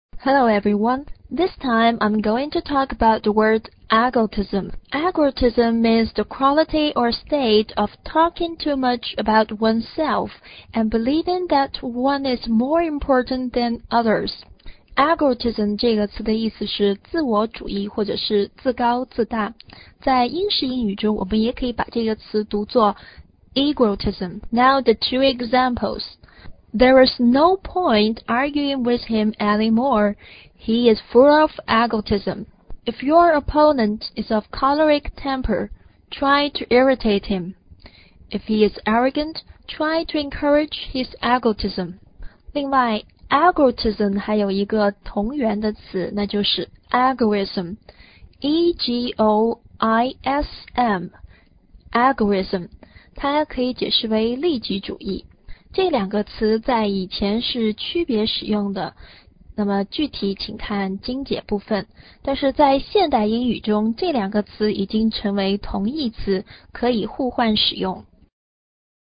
egotism有三个音节，第一个音节重读，可以发为[i:]，也可以发为[e:]。